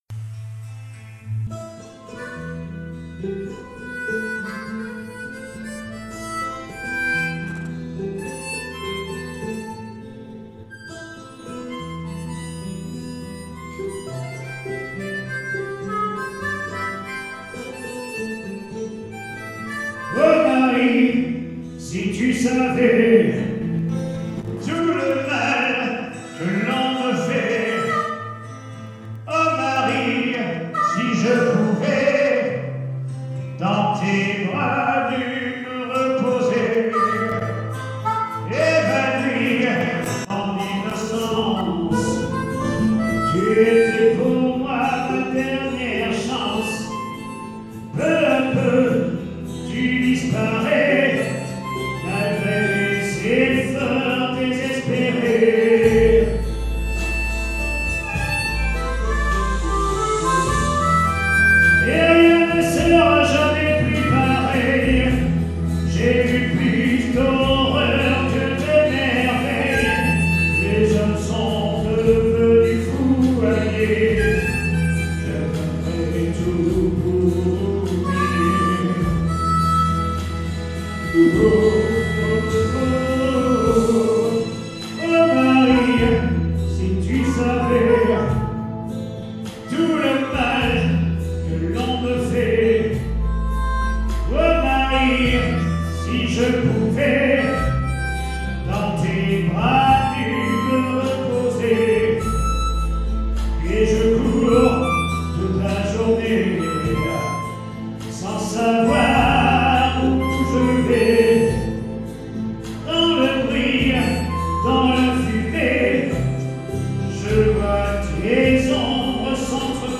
SOIREES BLUES-ROCK RETROSPECTIVE
maquettes